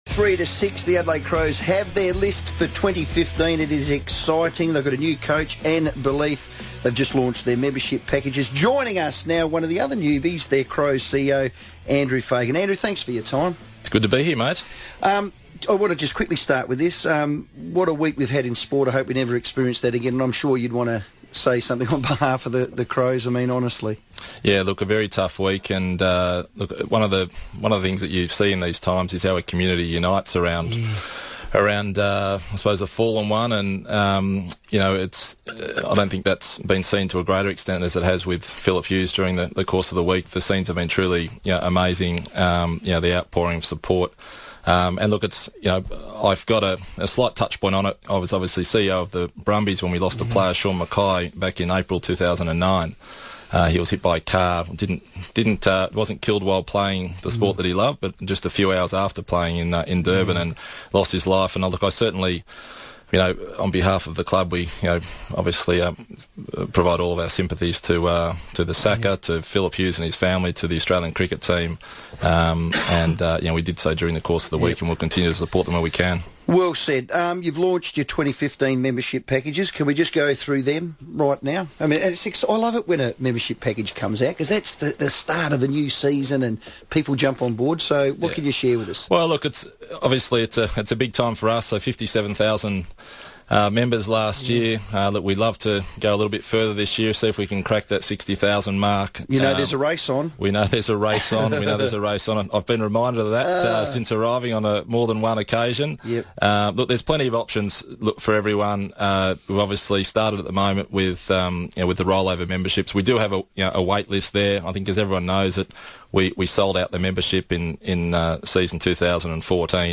live in the studio following the 2014 Rookie Draft